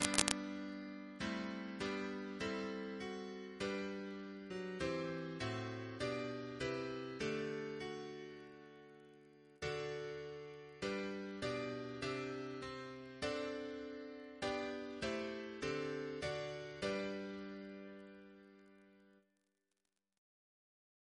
Double chant in G Composer: Stephen Darlington (b.1952) Reference psalters: ACP: 40